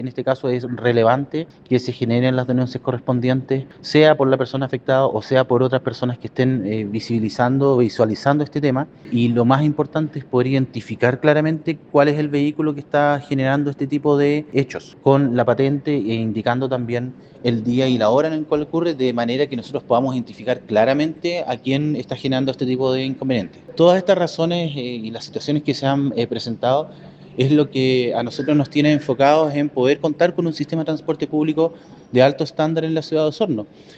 La autoridad de transporte remarcó la importancia de la denuncia, que puede llegar ya sea por quien sufre algún accidente o quienes lo observan en condición de testigos.